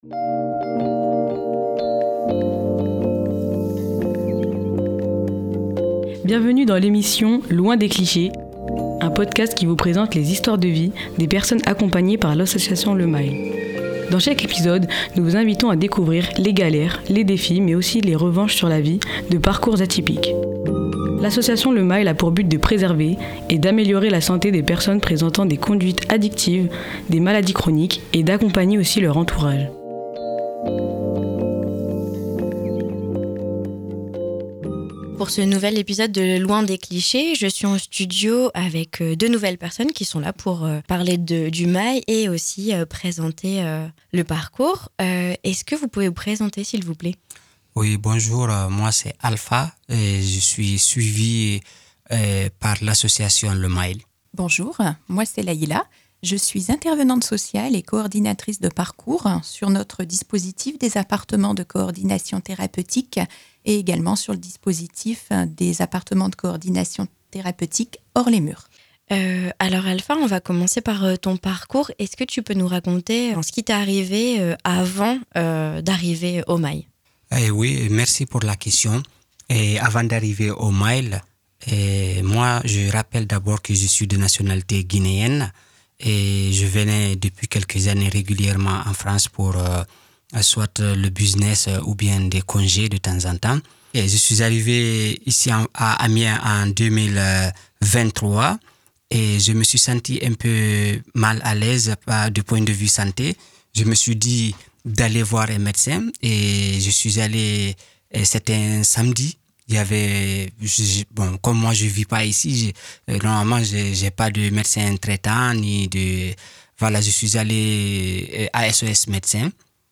Ce quatrième épisode est spécial car nous vous proposons d'écouter une table ronde organisée avec les professionnel.les de l'association afin de discuter de l'accompagnement pluridisciplinaire de l'addictologie.